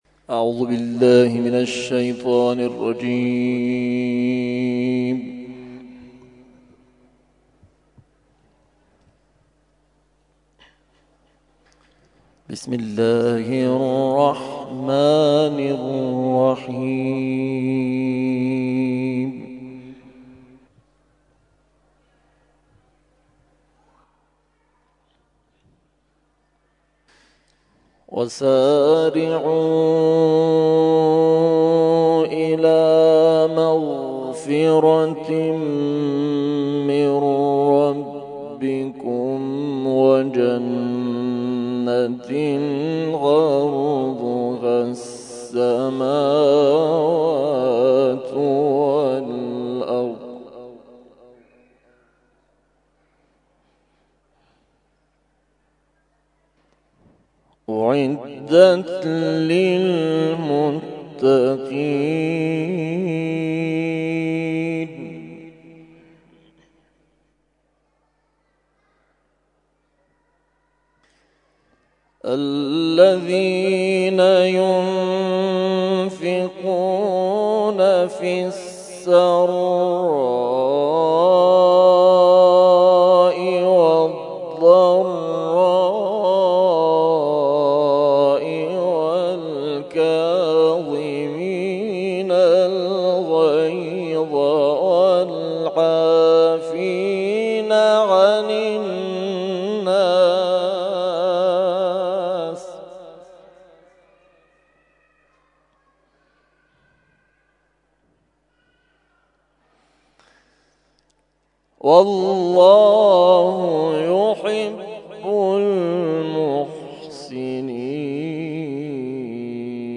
تلاوت مغرب